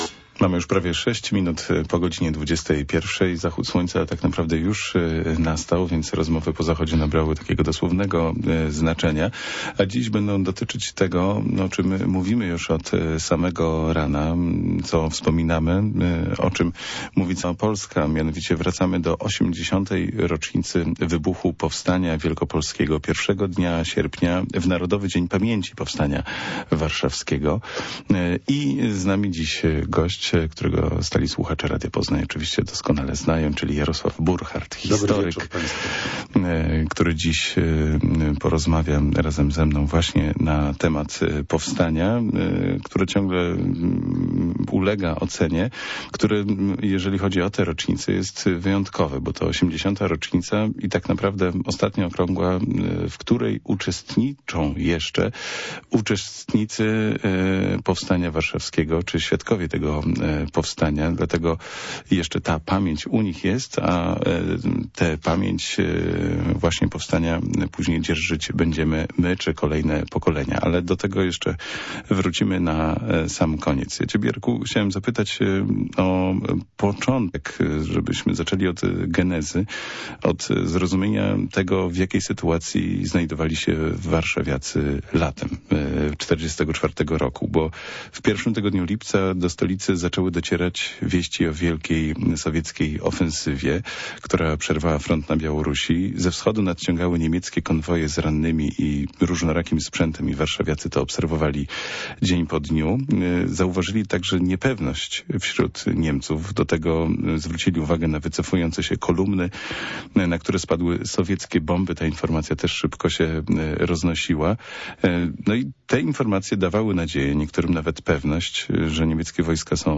W audycji poruszono kluczowe kwestie dotyczące Powstania Warszawskiego. Głównym tematem rozmowy była geneza wydarzeń z lata 1944 roku, w tym nastroje panujące wśród warszawiaków oraz wpływ wiadomości o sowieckiej ofensywie na decyzję o wybuchu powstania. Audycja odpowiadała na pytania dotyczące procesu podejmowania decyzji o rozpoczęciu walk, skali przygotowań oraz uzbrojenia powstańców.